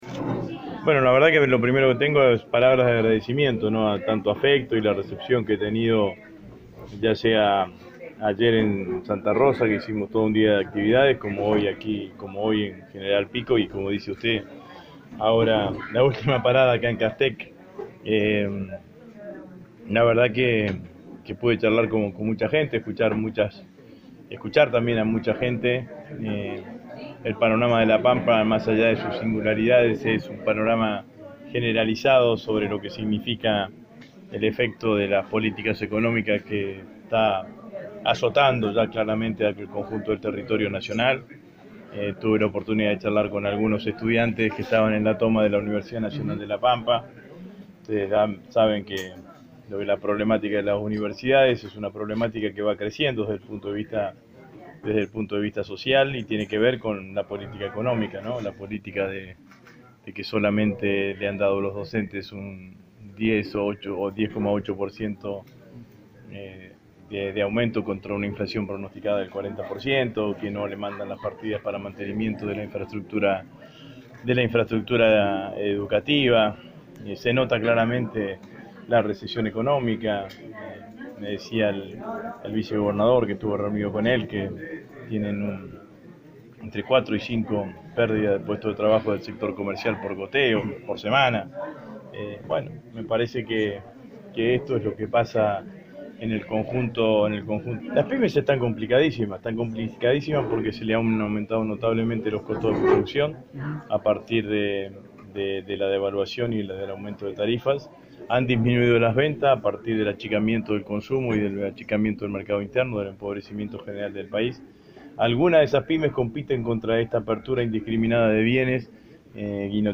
El diputado nacional Agustín Rossi estuvo este fin de semana en la provincia de La Pampa, el sábado en horas de la tarde lo hizo en la Cámara de Comercio de esta localidad ante unas 30 personas, en dialogo con la prensa dijo que si Cristina no se presenta él será el candidato a presidente para suceder a Macri, además aseguró que los testigos presentados por Bonadío en los allanamientos a la ex presidente son militantes de Cambiemos.